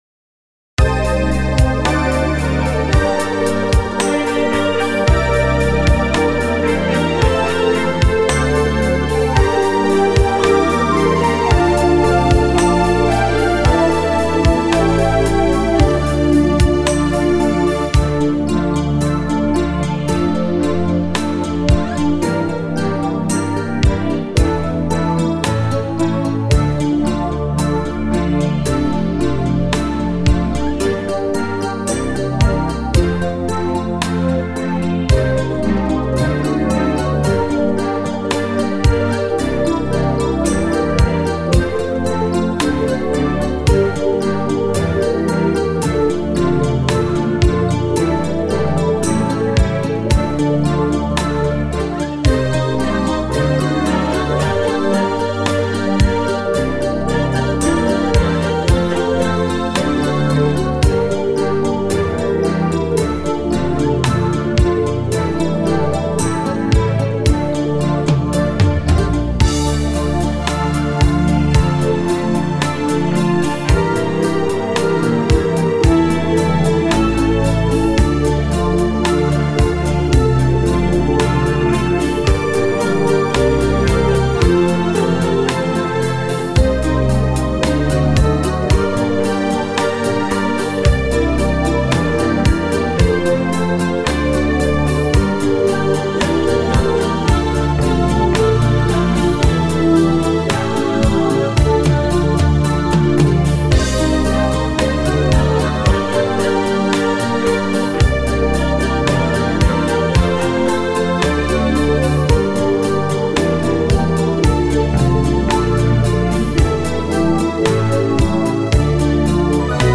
Застольные